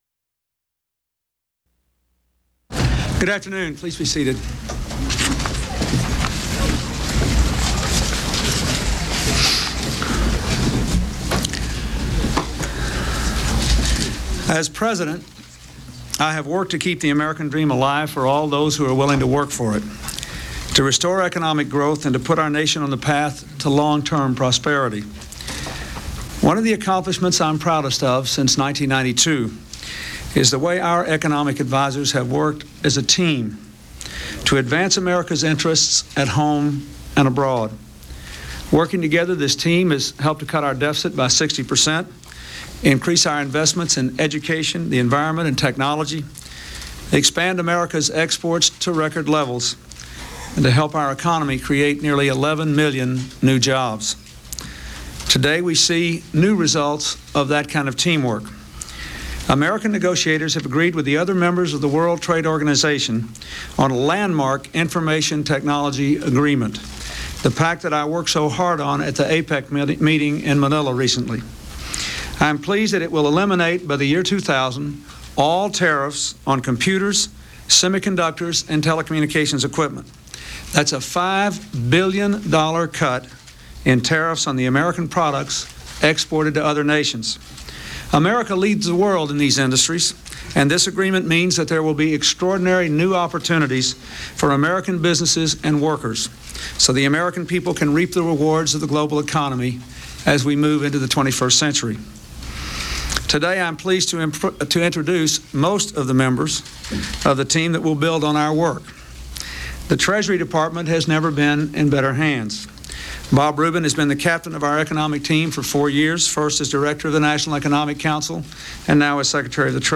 U.S. President Bill Clinton's press conference announcing the appointment of his economic team for his second term